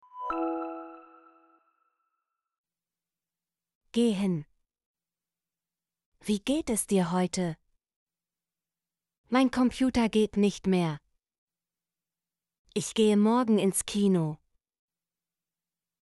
gehen - Example Sentences & Pronunciation, German Frequency List